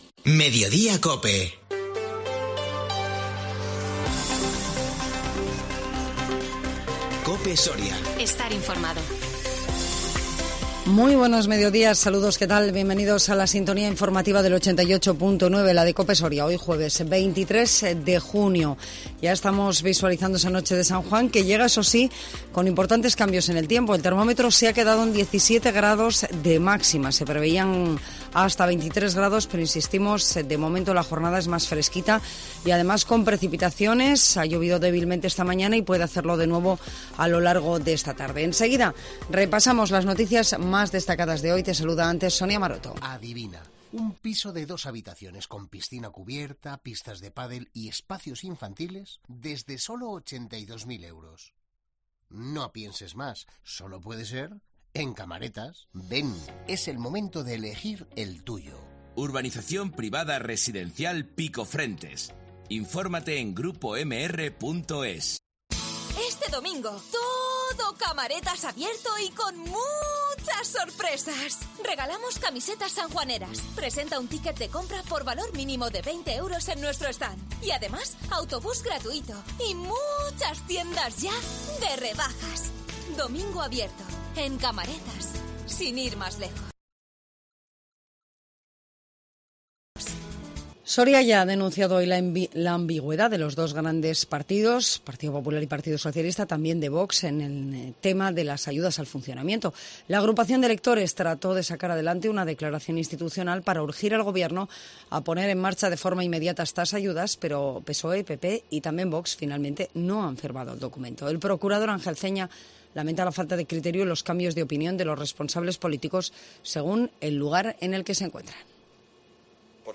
INFORMATIVO MEDIODÍA COPE SORIA 23 JUNIO 2022